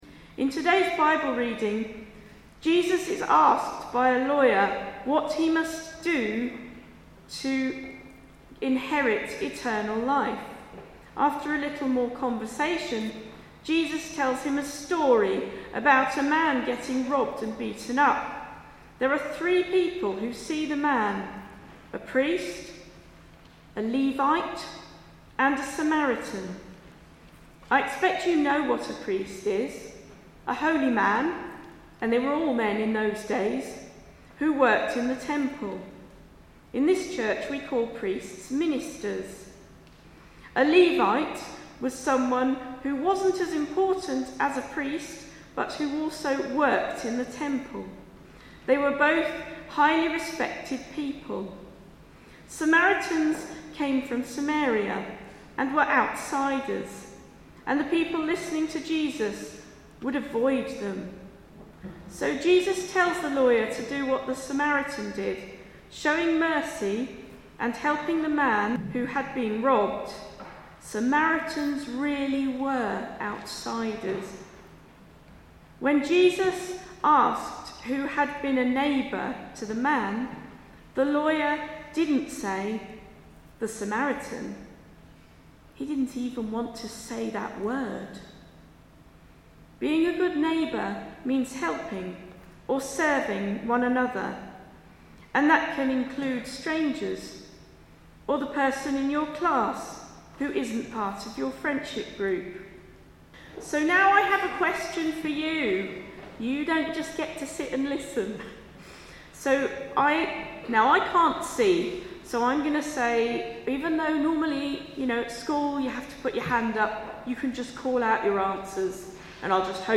Love your neighbour: knowing when and how to help; my sermon at a parade service 18 May 2025